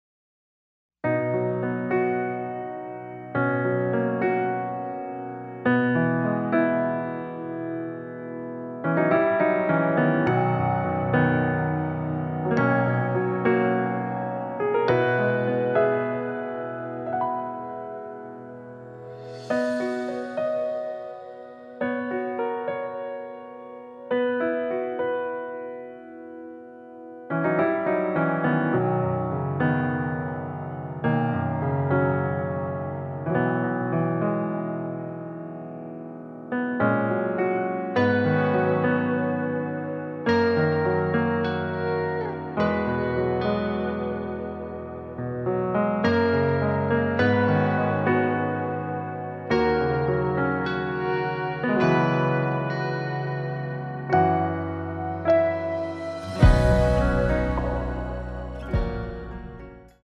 앞부분30초, 뒷부분30초씩 편집해서 올려 드리고 있습니다.
중간에 음이 끈어지고 다시 나오는 이유는
공식 MR